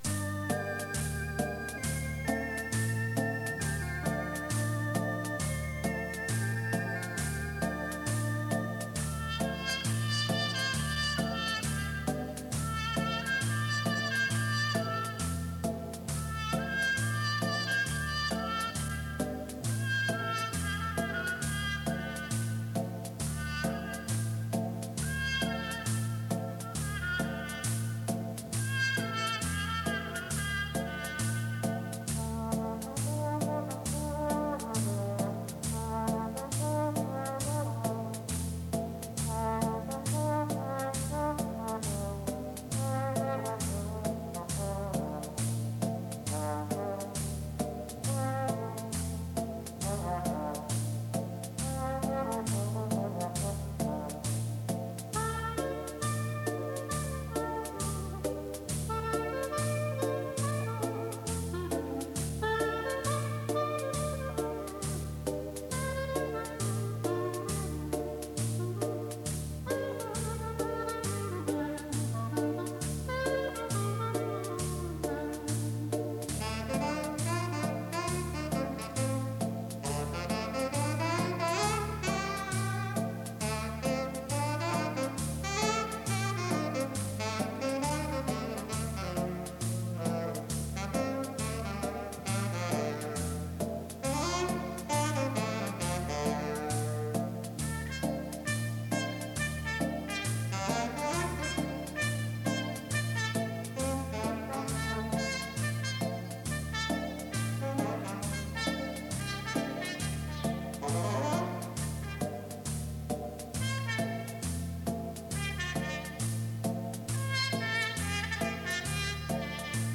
快四